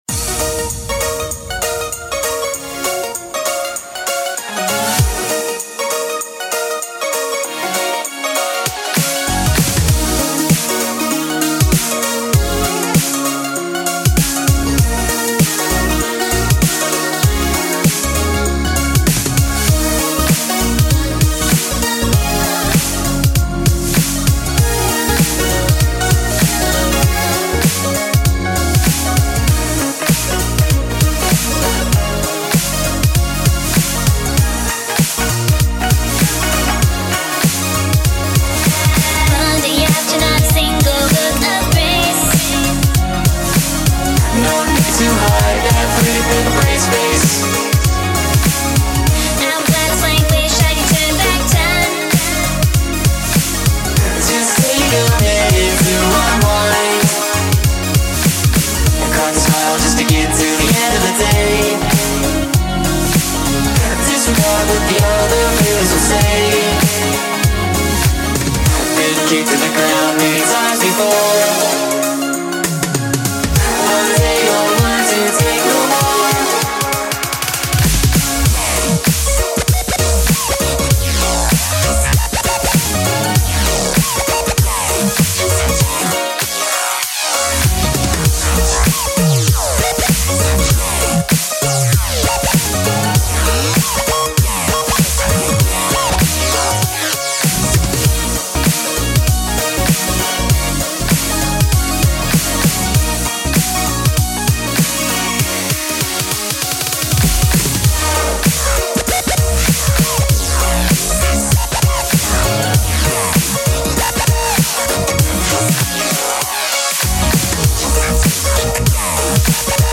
BPM - 98
4:50 ~~~~~~~~~~ Genre - Glit